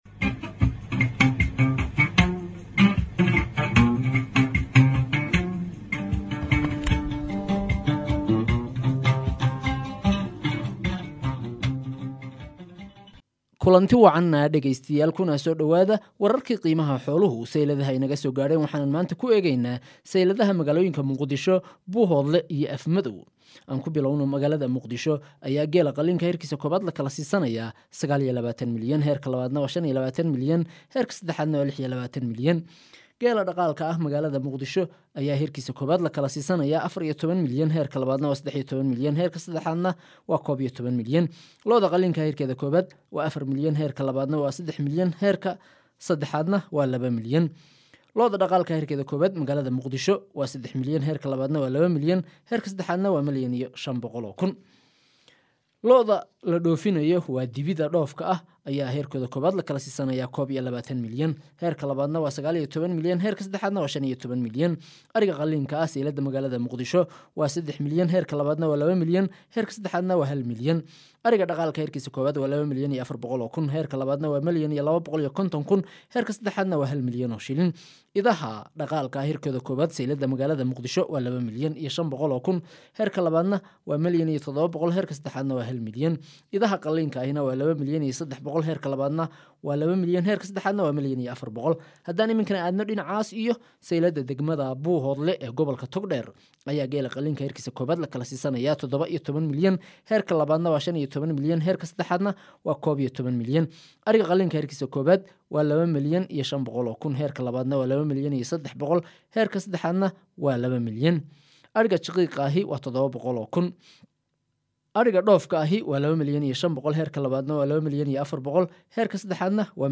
Dhageyso wararka qiimaha xoolaha